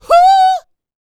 D-YELL 2901.wav